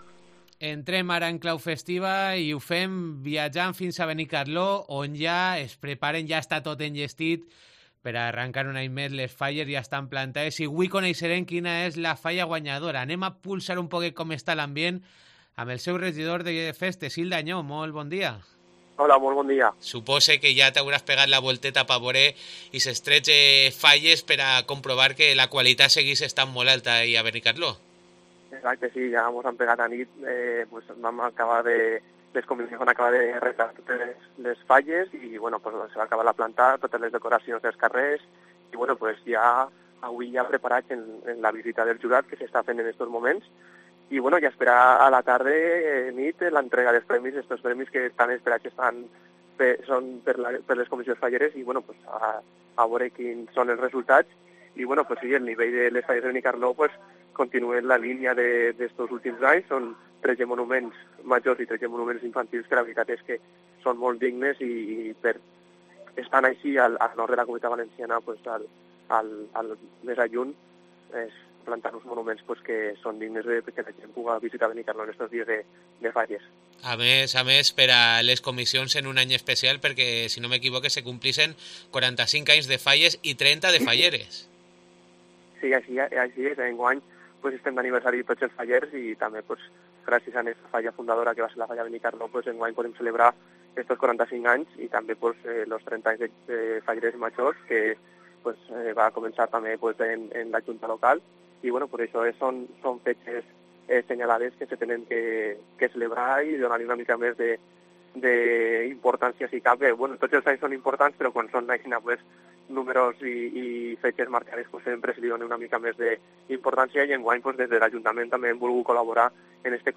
Entrevista a Ilde Añó (ajuntament de Benicarló)